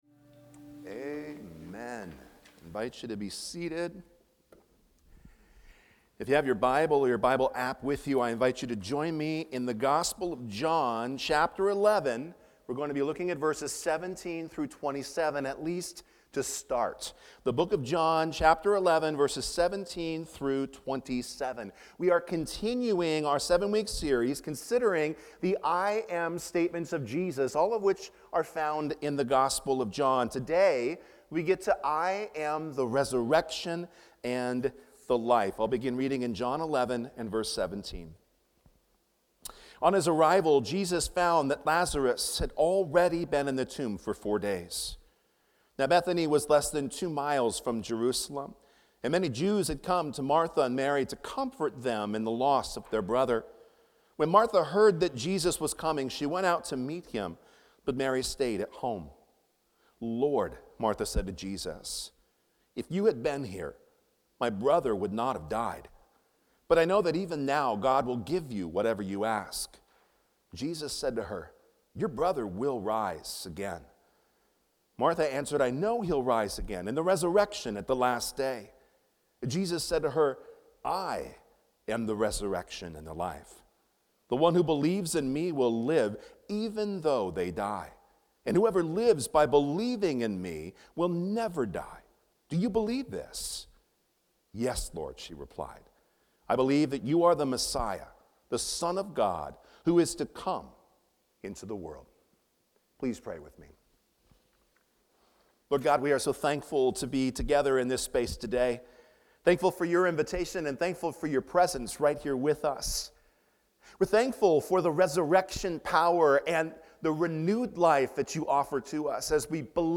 IAM... THE RESURRECTION & THE LIFE | Fletcher Hills Presbyterian Church